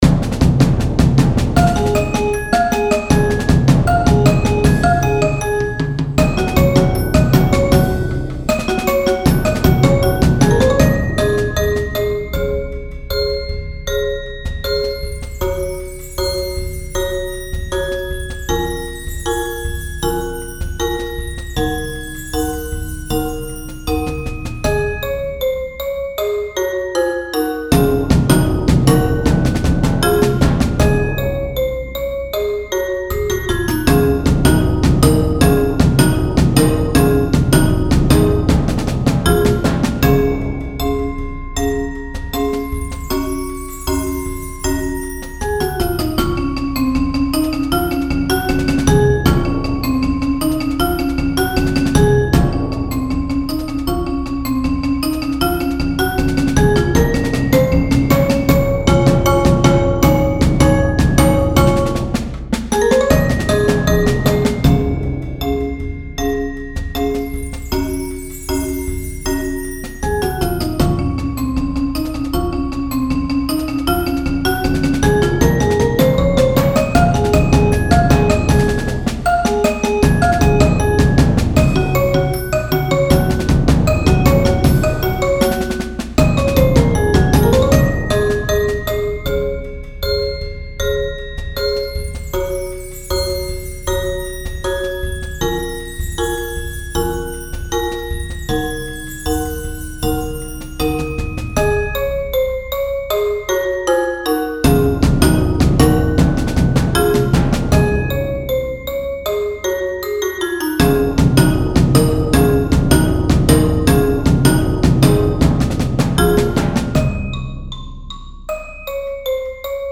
Genre: Percussion Ensemble
# of Players: 6-8+
Bells [may double or substitute with Vibraphone]
Timpani: [4 drums; 2-drum version included]*
Percussion 1: Snare Drum
Percussion 2: 2 Concert Toms, Wind Chimes
Percussion 3: Hi-Hat, Ride Cymbal
Percussion 4: Concert Bass Drum*